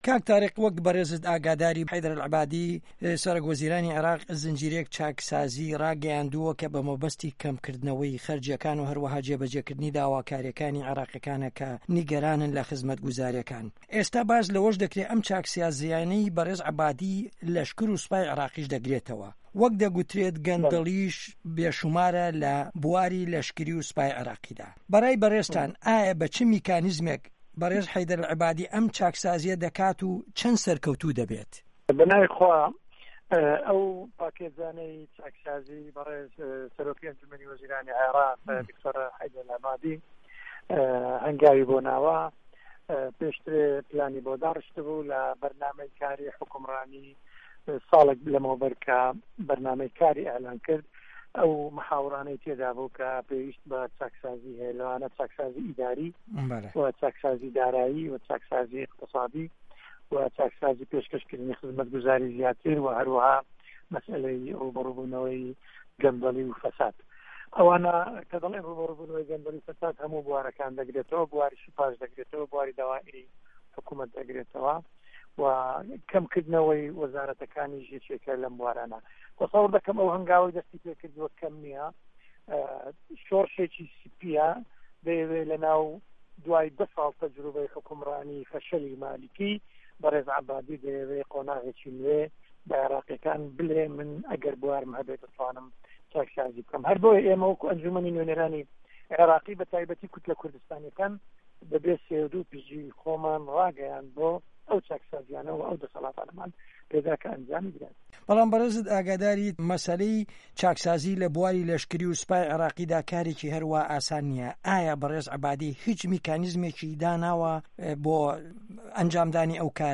وتوێژ لەگەڵ تاریق سدیق